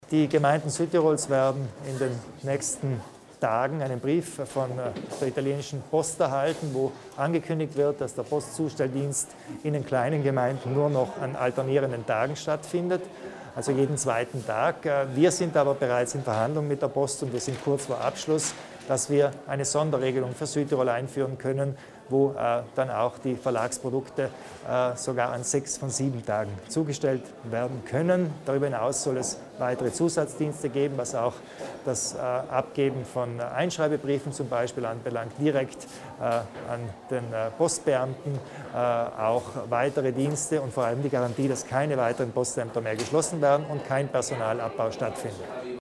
Landeshauptmann Kompatscher erläutert die Bemühungen um eine Sonderregelung bei der Postzustellung